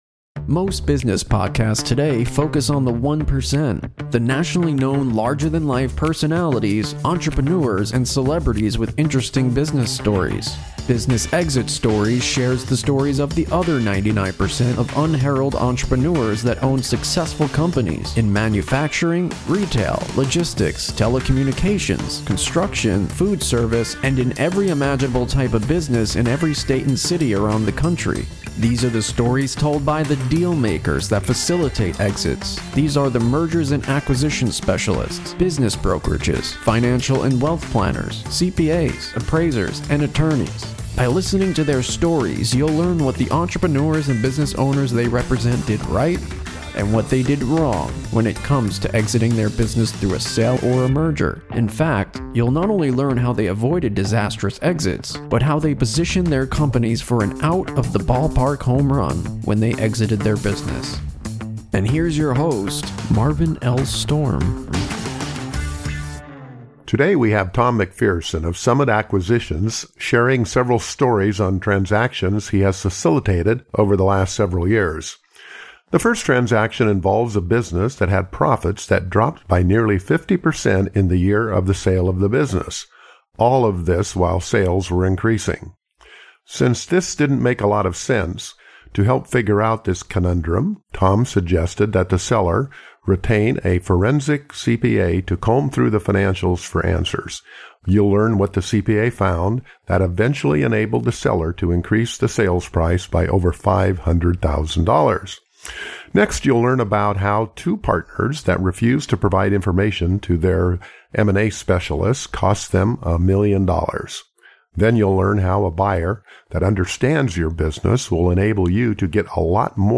4 Parts to this Interview